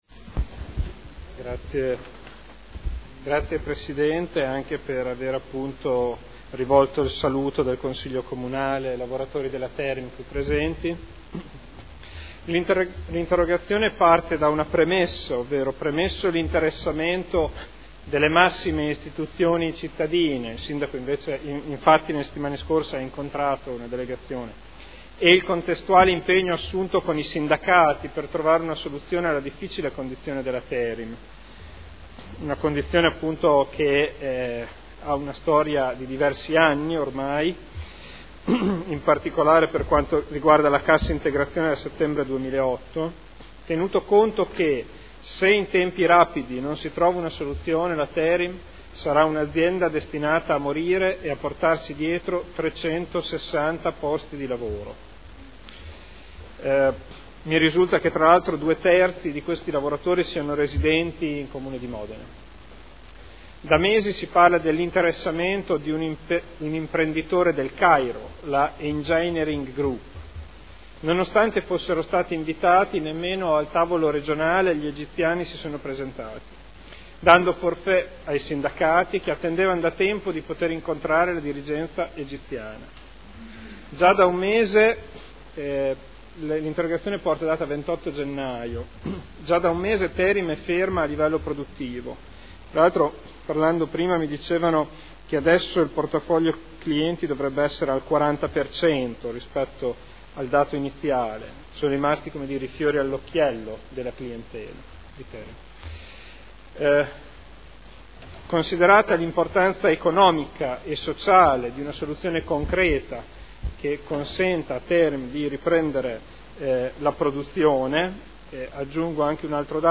Seduta del 11/03/2013. Interrogazione dei consiglieri Ricci (Sinistra per Modena), Trande (P.D.) avente per oggetto: “Salvare TERIM”